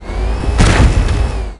wood.wav